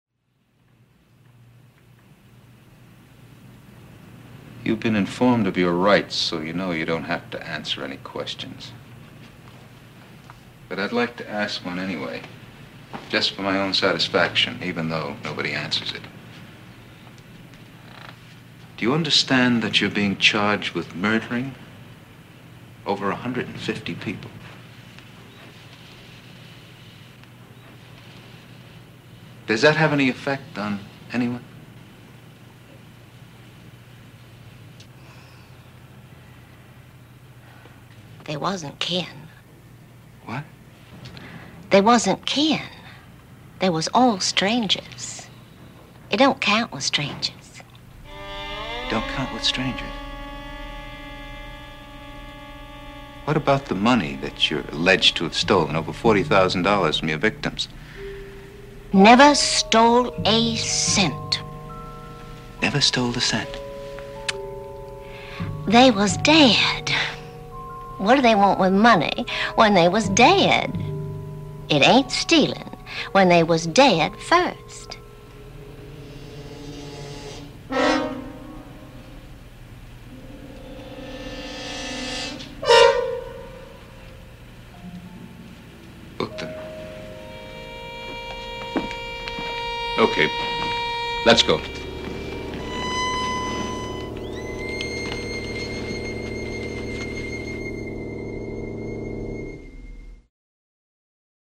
The episode featured Slim Pickings as Sam Ferguson and Barbara Baxley as Sadie Ferguson. Here is the closing scene.